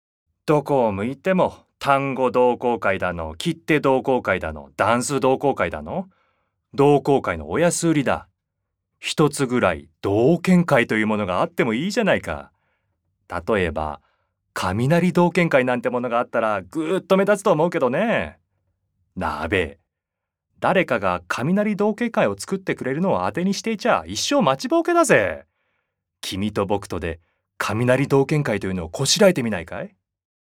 セリフ@
ボイスサンプル